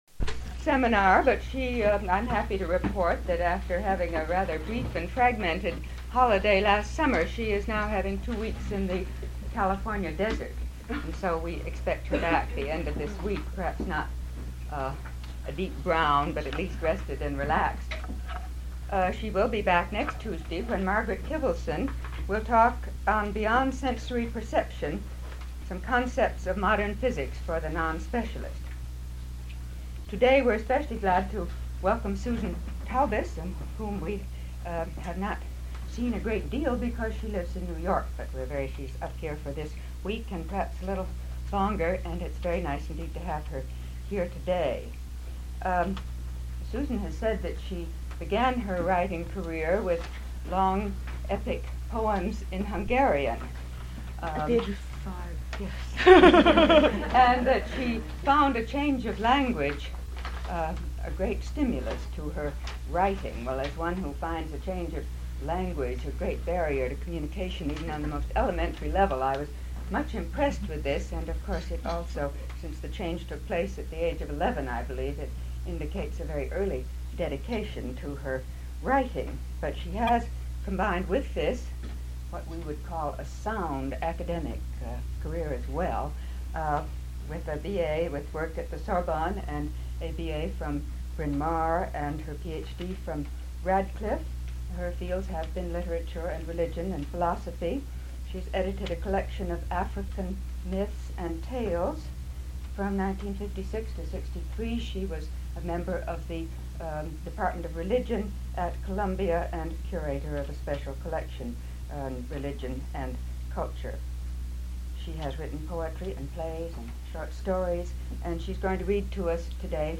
Susan Taubes leest voor en vertelt over haar roman Treurzang voor Julia, 25 januari 25, 1966.